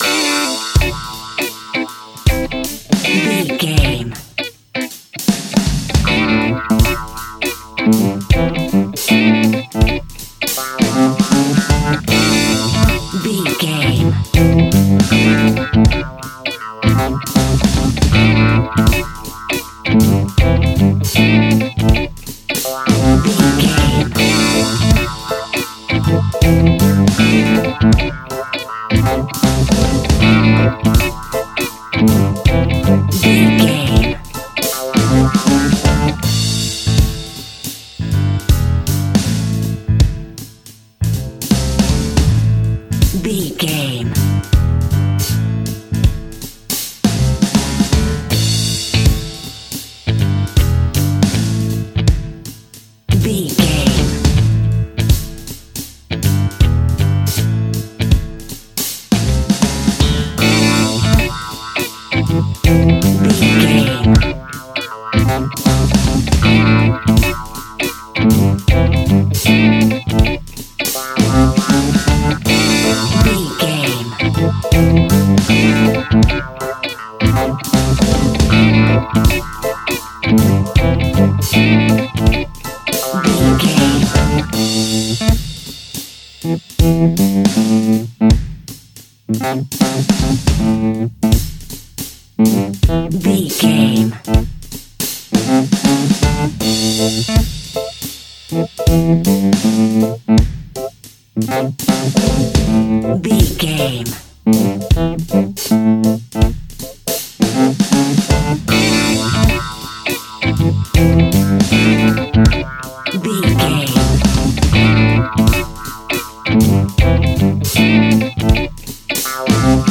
Aeolian/Minor
Slow
laid back
chilled
off beat
drums
skank guitar
hammond organ
percussion
horns